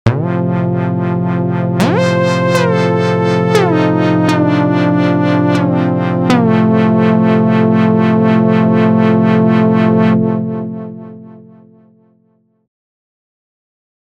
Single Cycle Unison Major Chord[Saw Wave]
Sounds from Ableton Sampler